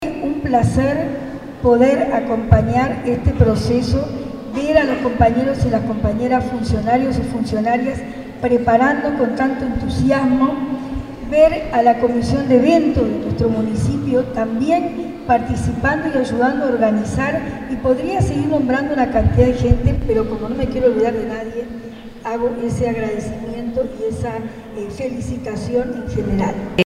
Encuentro artístico y municipal de la microrregión en el Municipio de Ciudad de la Costa
alcaldesa_sonia_misirian_0.mp3